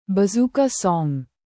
Oh Yes Efeito Sonoro: Soundboard Botão
Oh Yes Botão de Som